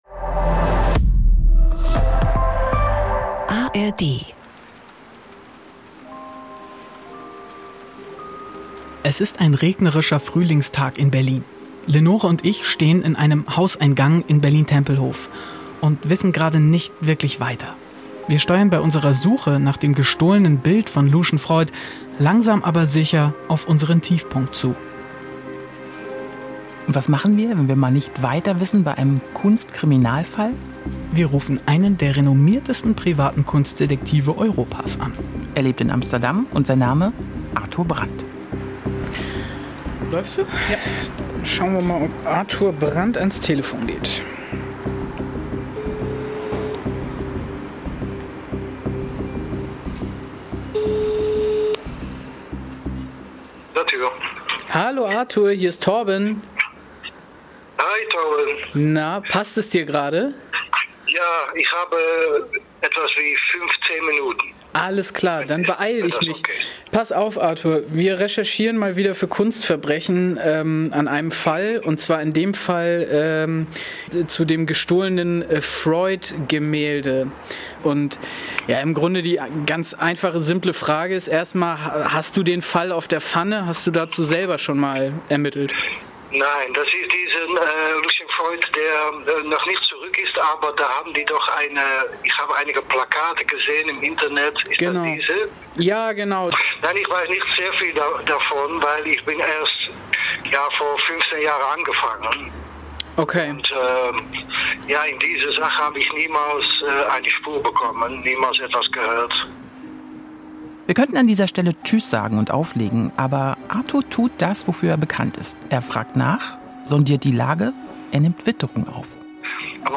Die Suche nach Bacons Kopf (5/7) - Der Zeuge ~ Kunstverbrechen - True Crime meets Kultur Podcast